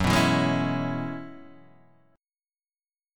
F# 9th Suspended 4th